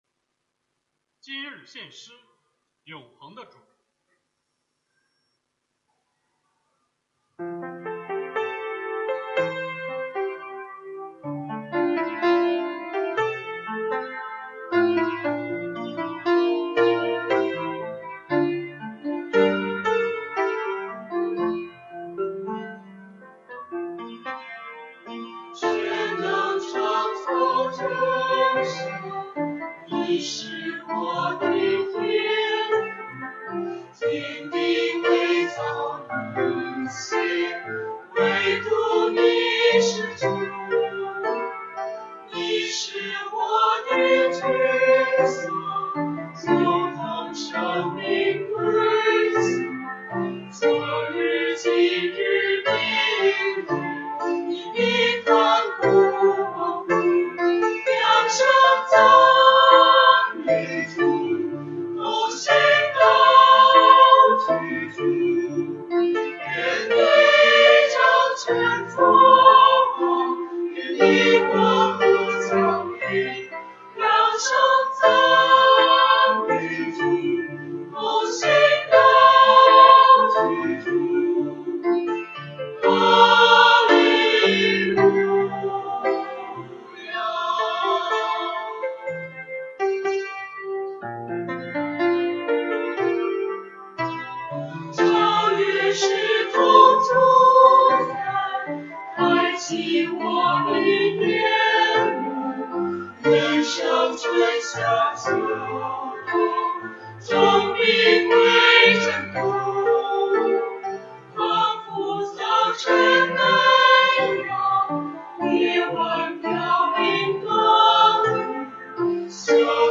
青年诗班
诗班献诗